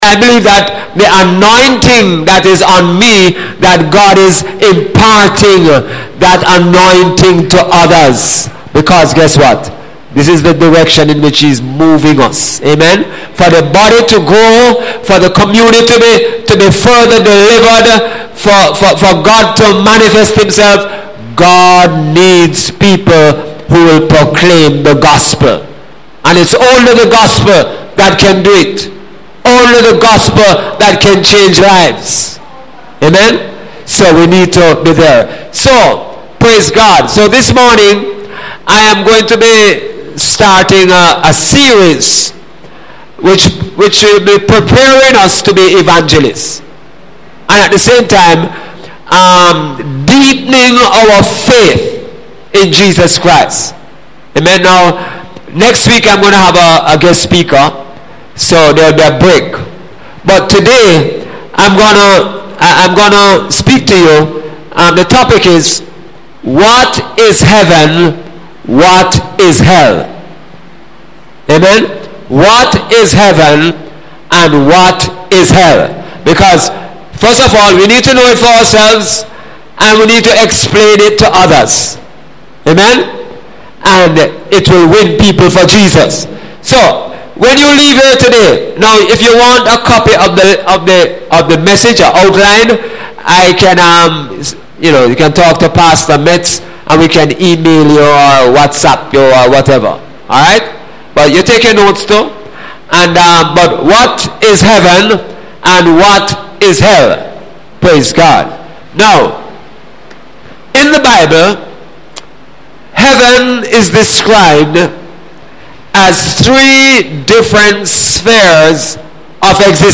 Sunday Sermon – April 30, 2017 – What is Heaven and What is Hell?